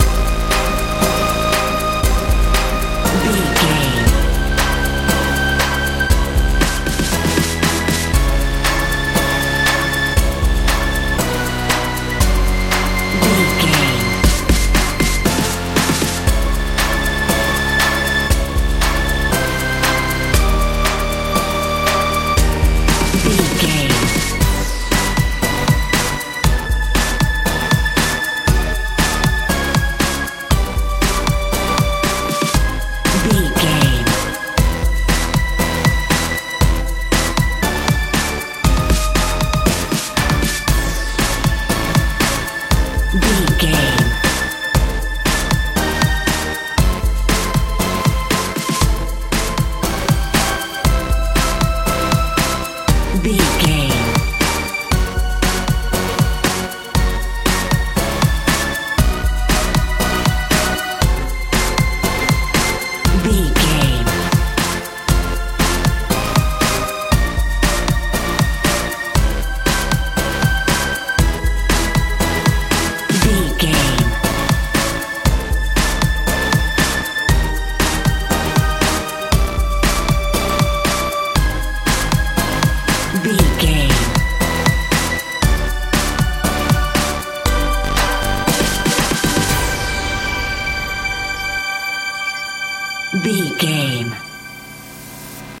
modern dance
Ionian/Major
E♭
futuristic
powerful
synthesiser
bass guitar
drums
80s
90s